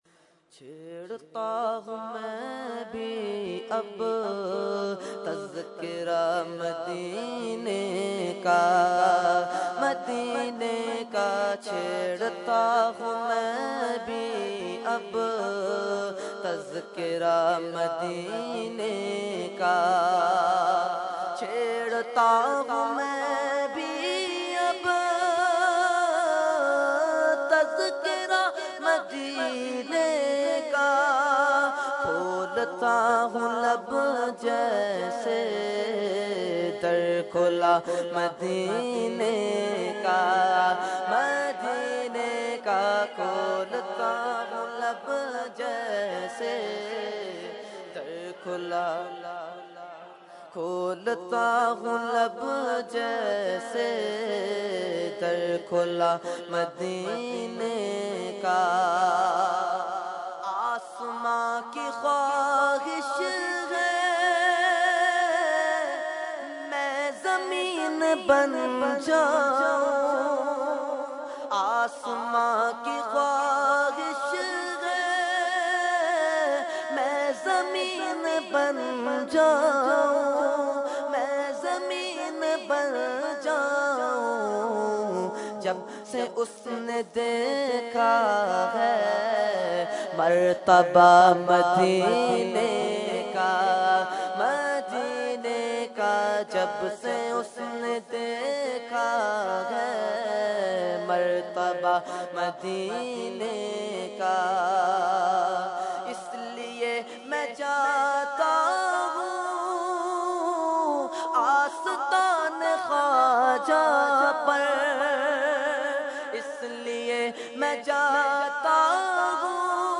Category : Naat | Language : UrduEvent : Urs Ashrafia Memon Masjid Muslehuddin 2013